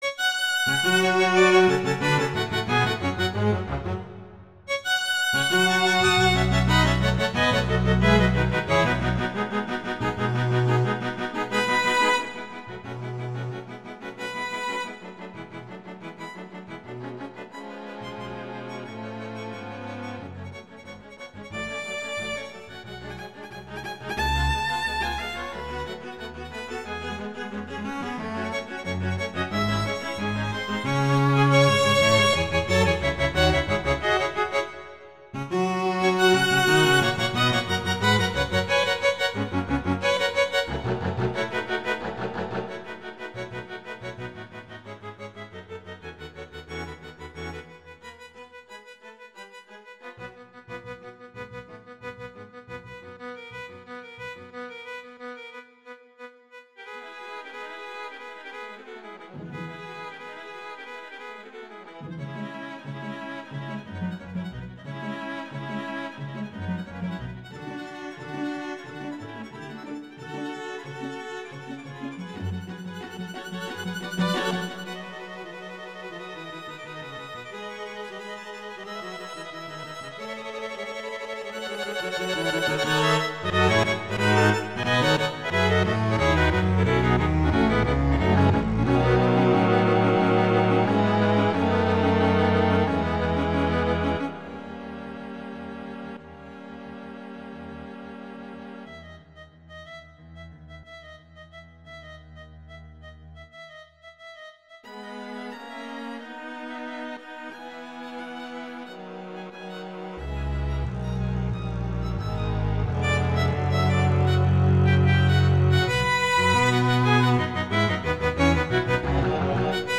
classical
E major
♩=180 BPM (real metronome 176 BPM)